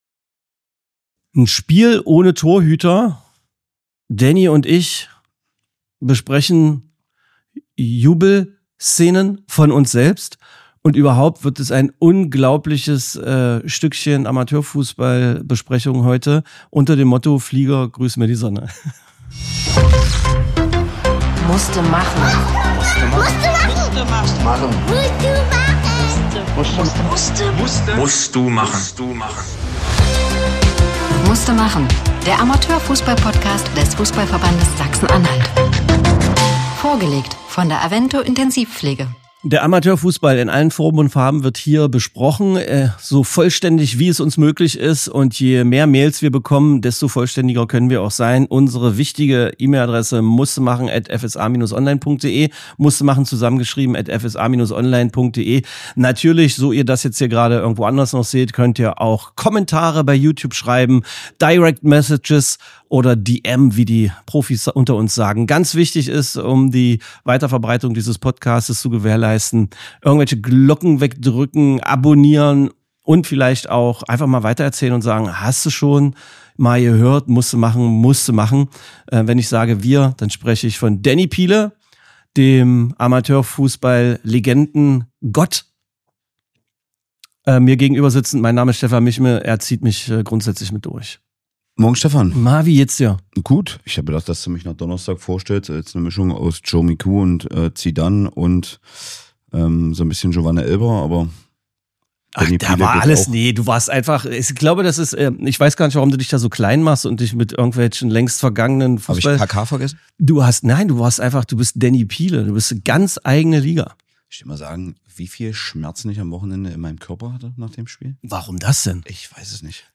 Es wird gejubelt, geflucht, analysiert – und natürlich erzählt: von Schiedsrichterlegenden, kuriosen Spielsituationen und einem Spiel ohne Torhüter.